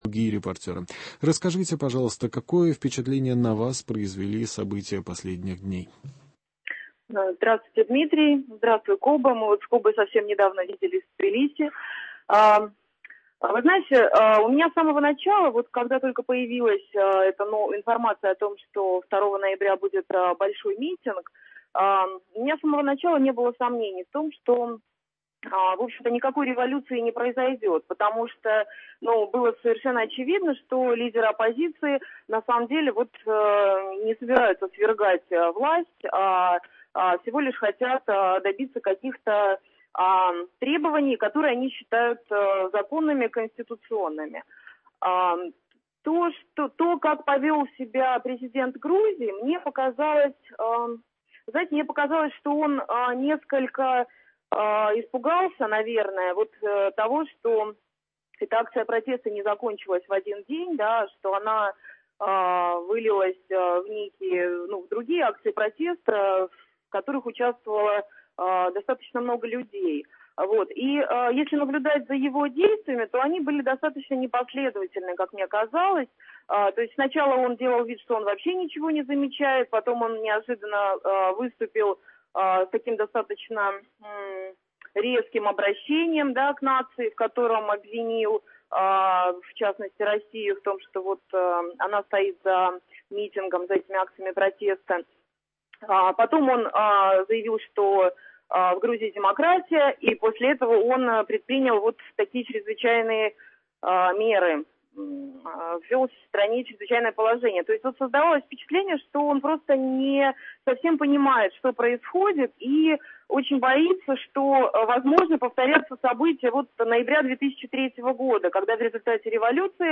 Беседа с экспертами о ситуации в Грузии, обзор американских еженедельников и российских Интернет-изданий, хроника неполитических событий, рубрики «Курьез недели» и «Человек недели».